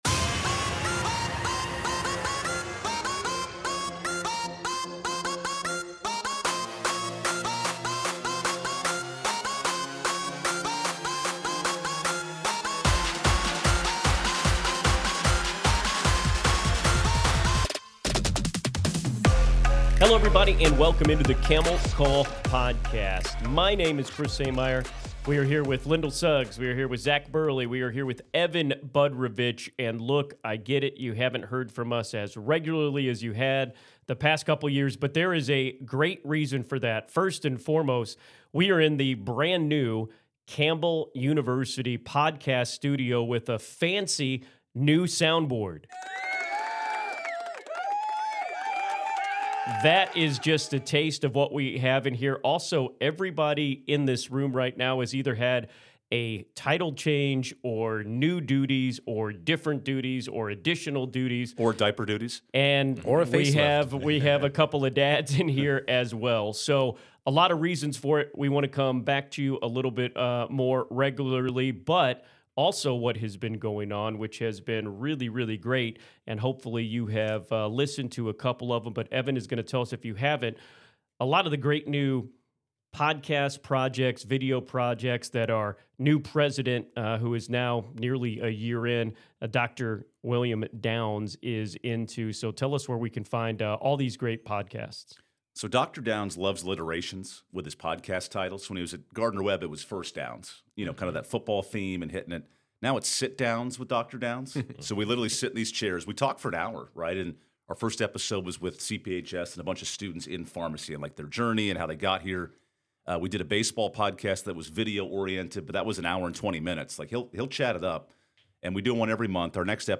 Women's Lacrosse is on a seven-game win streak as our Softball and Baseball teams look poised to get back to the top of the CAA. Recorded in the new Campbell podcast room, it is a supersized edition of Camel Call!